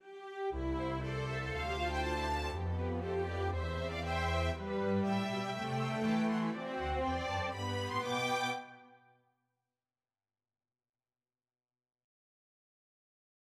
여기서 다섯 개의 선율이 "풍부한 대화의 태피스트리"에서 동시에 결합된다.[15]